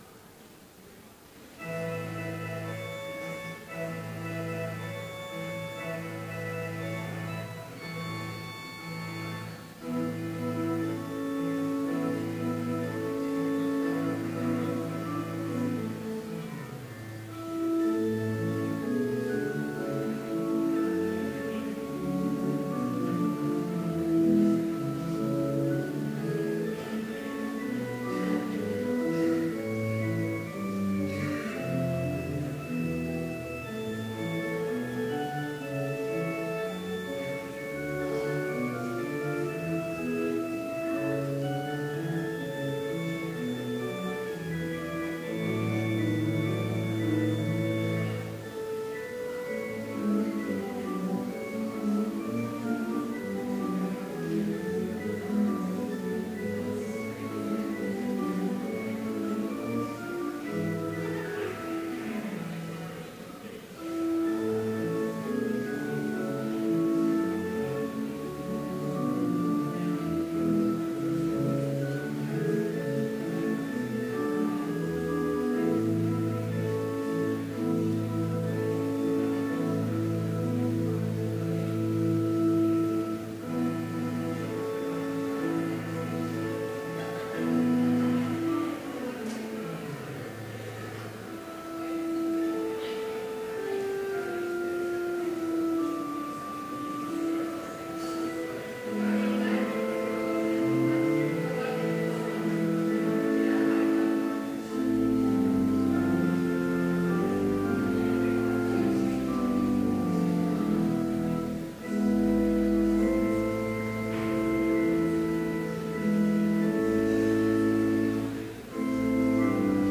Complete service audio for Chapel - March 22, 2016
Hymn 279, Hosanna, Loud Hosanna Reading: Matthew 21:6-11 Devotion Prayer Hymn 280, Ride On, Ride One in Majesty Blessing Postlude Scripture Matthew 21:6-11 So the disciples went and did as Jesus commanded them.